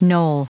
Prononciation du mot knoll en anglais (fichier audio)
Prononciation du mot : knoll